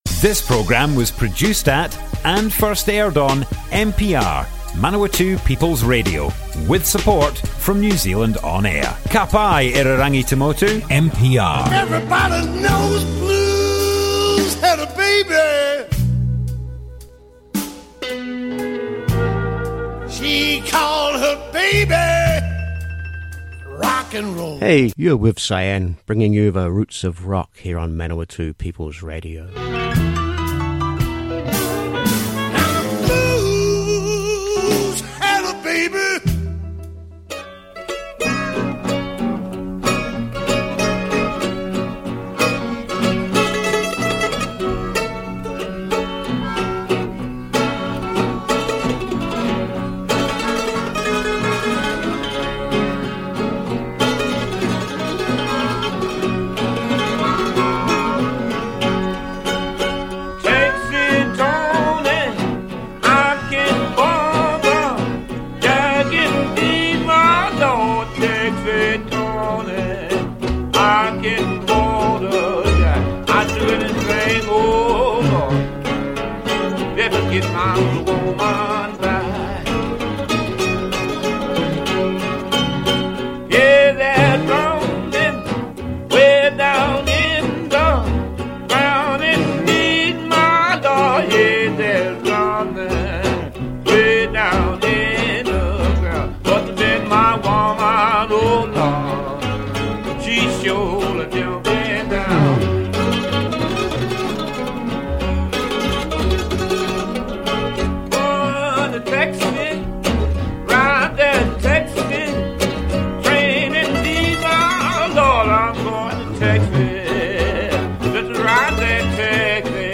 Two hours of the blues